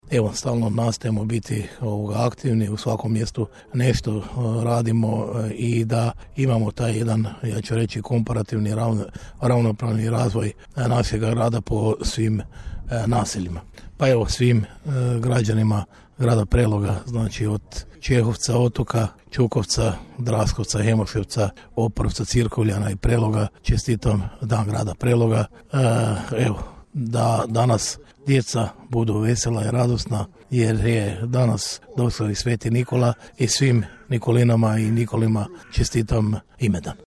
Uz čestitku povodom Dana grada, gradonačelnik je poručio: